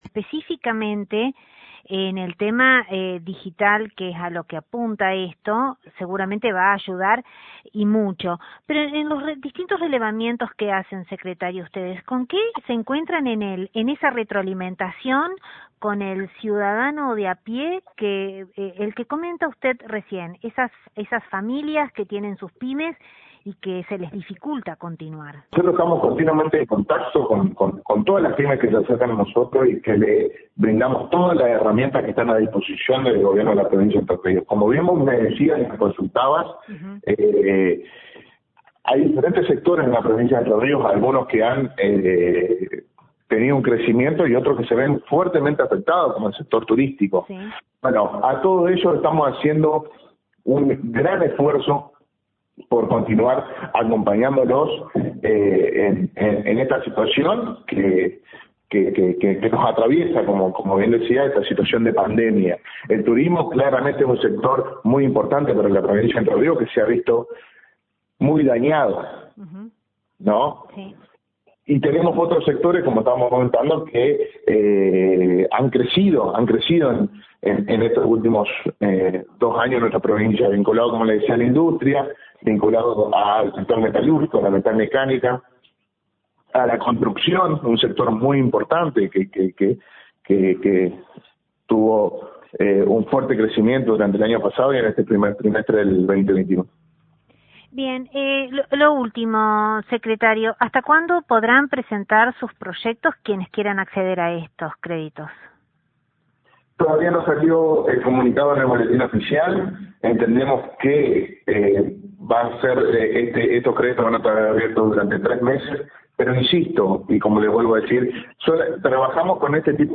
Sobre la línea crediticia para la digitalización de pymes hablamos con el Secretario de Desarrollo Económico de la provincia, Pedro Gebhart